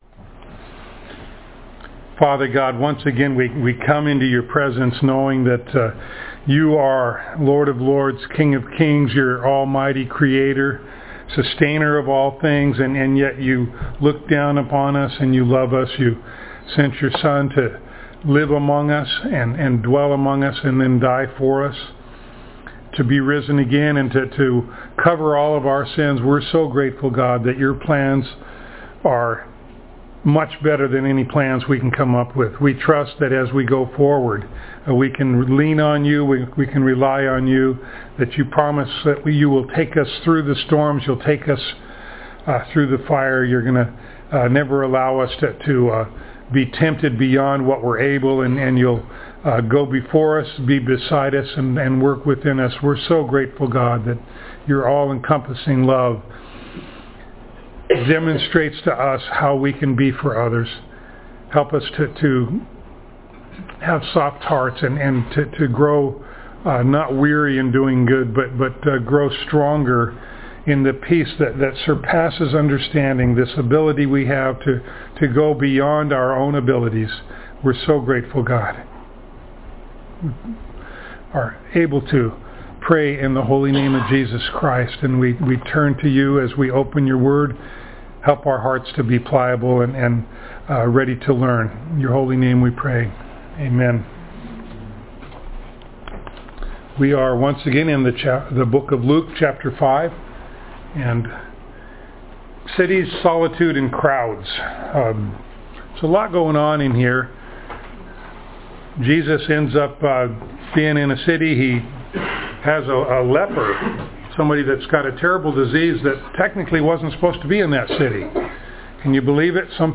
Luke Passage: Luke 5:12-26 Service Type: Sunday Morning Download Files Notes « A Day by the Lake Tax Collectors